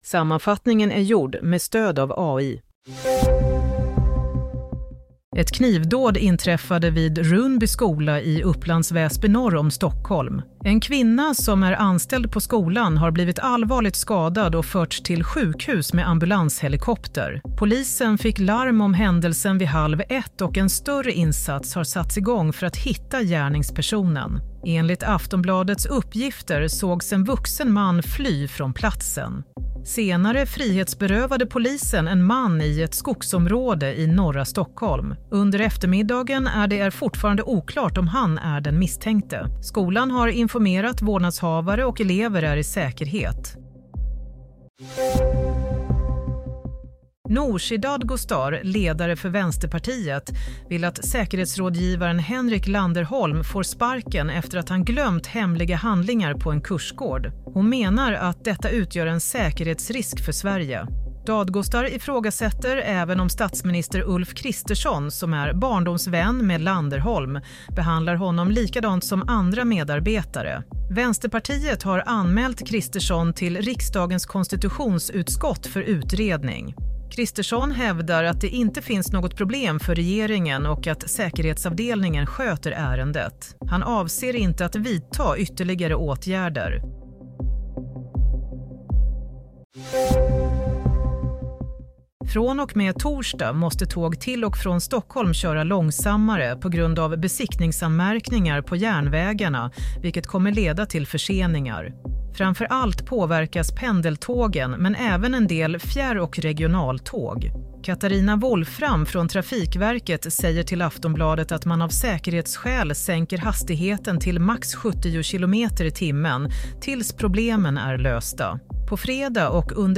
Play - Nyhetssammanfattning – 15 januari 16:00
Sammanfattningen av följande nyheter är gjord med stöd av AI. – Kvinna skadad i knivdåd vid skola – Kravet på Kristersson: Ge Landerholm sparken – Tågen behöver köra långsammare Broadcast on: 15 Jan 2025